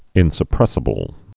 (ĭnsə-prĕsə-bəl)